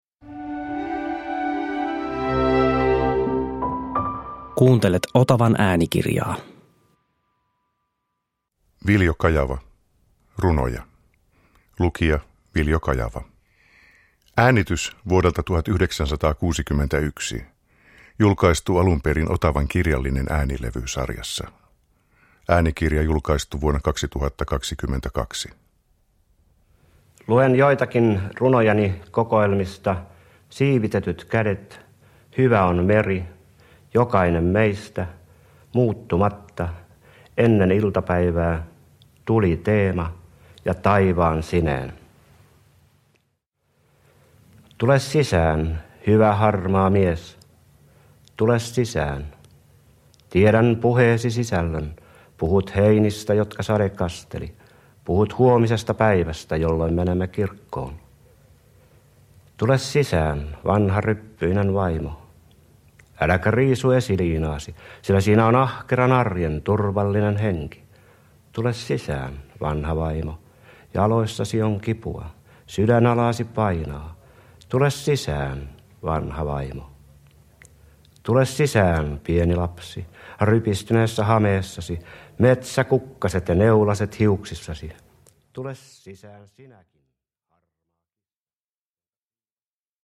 Runoja – Ljudbok – Laddas ner
Vapaan rytmin puolustaja lukee runojaan.
Tälle äänitteelle vuodelta 1961 Viljo Kajava on itse lukenut 16 runoa.
Uppläsare: A. W. Yrjänä, Viljo Kajava